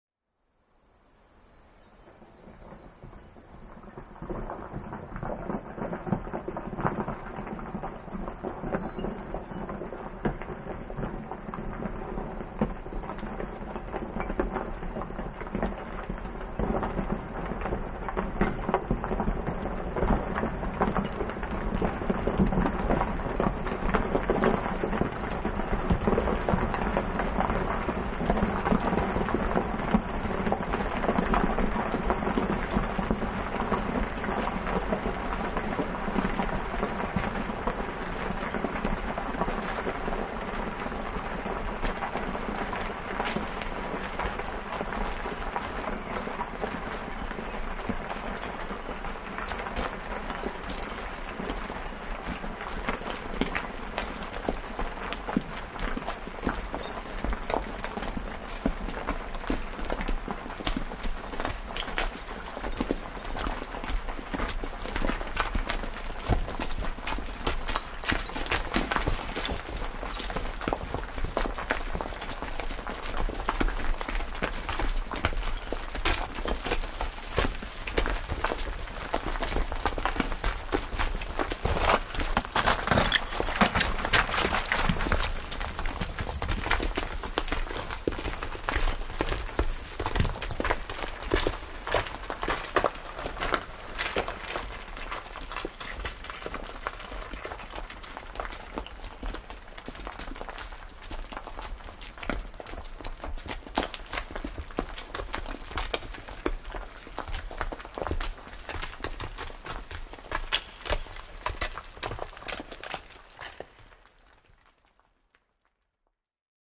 A long, cascade of many small rocks tumbles down the steep valley walls of the upper Yentna Glacier.